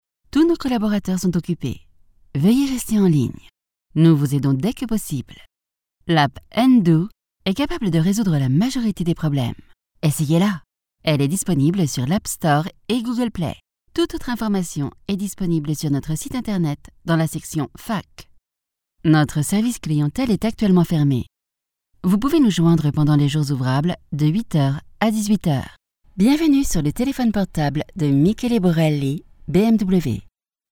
Minha voz é calorosa, feminina e adequada para narração, elearning, audiolivros, audioguias, mas também alguns comerciais, jogos.
Micro Neumann 103
Uma cabine Isovox em uma cabine à prova de som